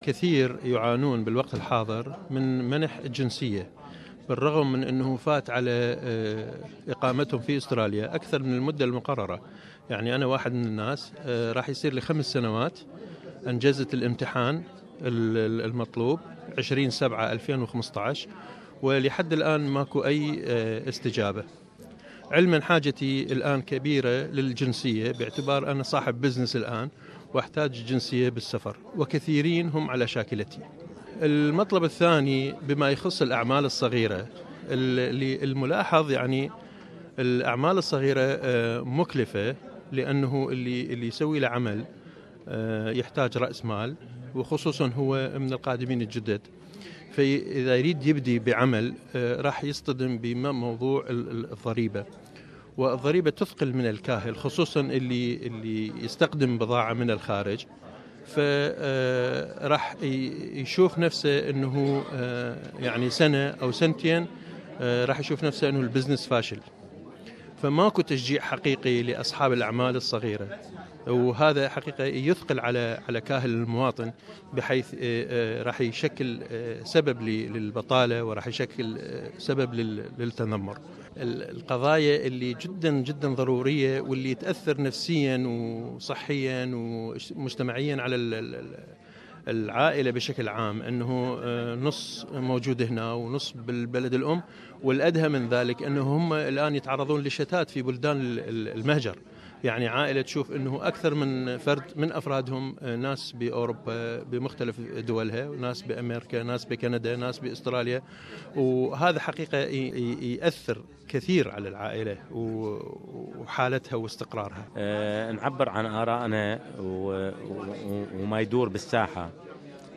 خلال اللقاء الانتخابي بين مرشحي الأحزاب والناخبين الذي دعا اليه مركز موارد المهاجرين في منطقة ليفربول . استطلعنا اراء الناخبين في مناطق المقاعد الانتخابية الثلاث Werriwa, Huges , Fowler في نيو ساوث ويلز